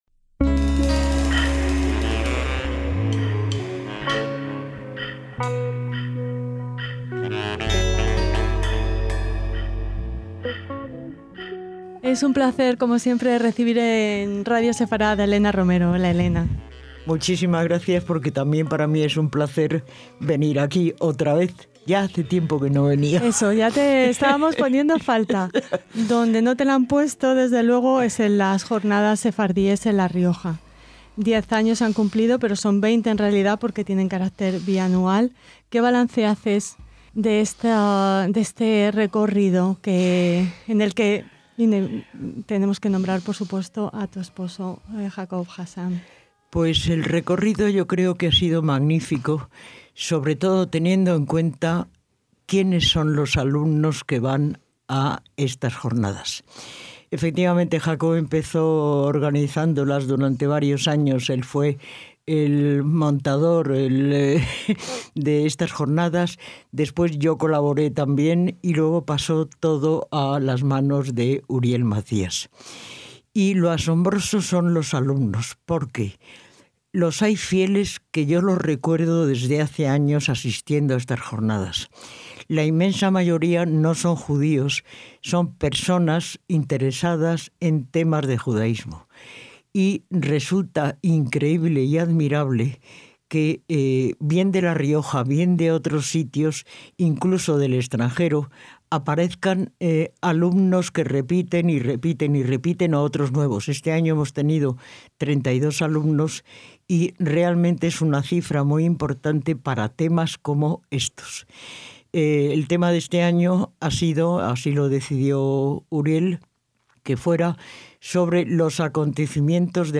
DESDE LAS X JORNADAS SEFARDÍES EN LA RIOJA – Alumnos, oyentes y expertos de diferentes universidades nacionales e internacionales tienen en sus manos unos textos seleccionados acerca de la circuncisión.